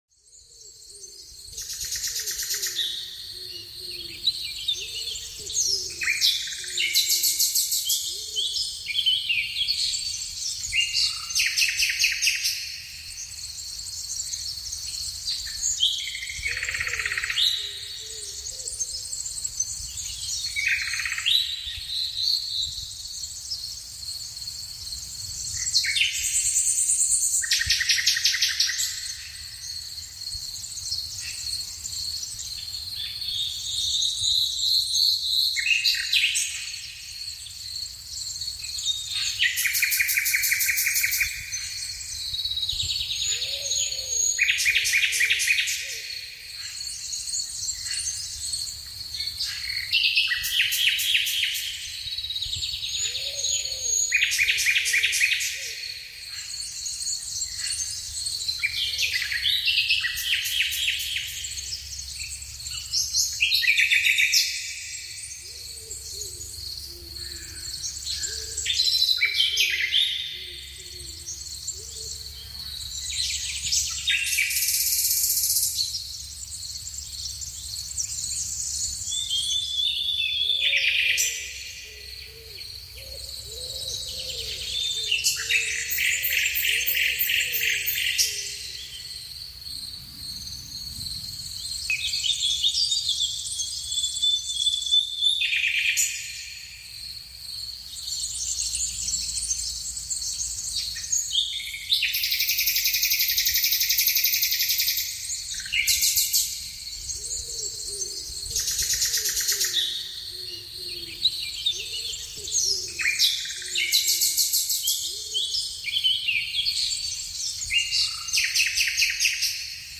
Blumenfeld-Frühlingslicht: Vogelgesang + Morgenlicht = pure Entspannung